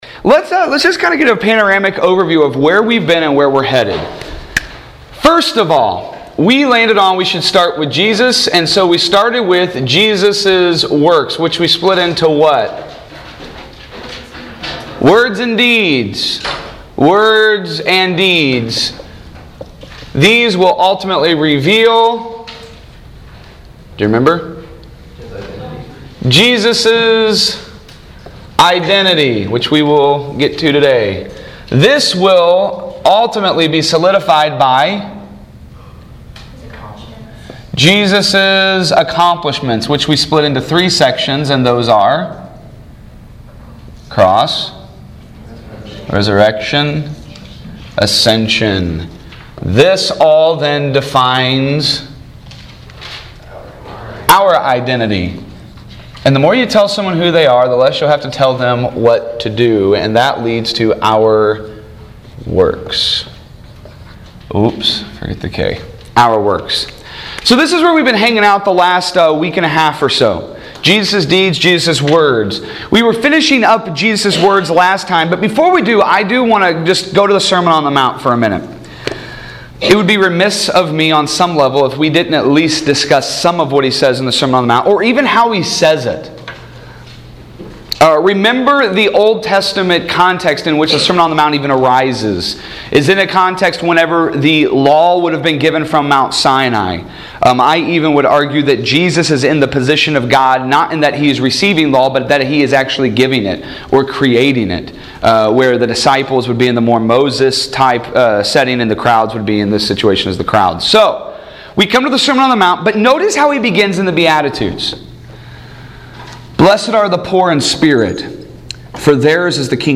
Audio Lectures
TITLE: Theology of Biblical Justice PLACE: Ozark Christian College (Joplin, MO) NUMBER OF LECTURES: 25 audio lectures